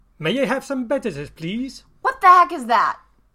BEdedos-2.mp3